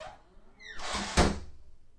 门打开 关闭 2
描述：房子里面的门开和关